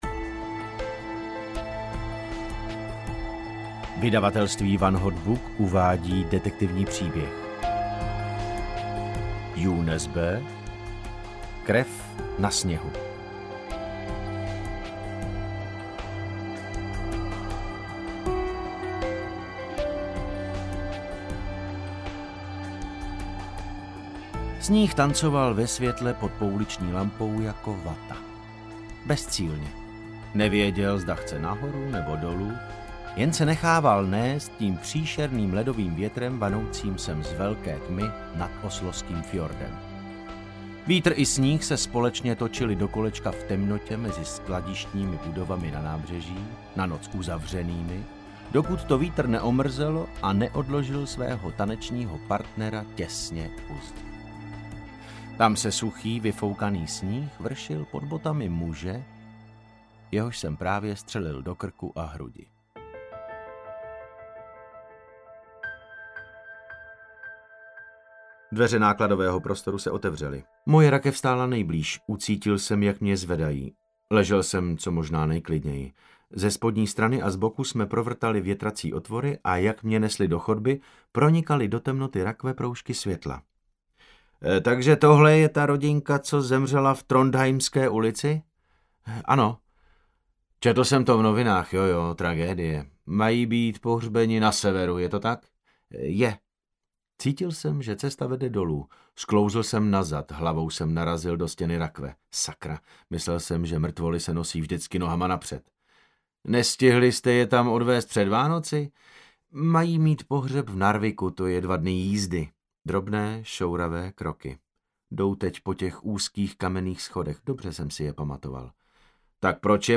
Krev na sněhu audiokniha
Ukázka z knihy
• InterpretDavid Novotný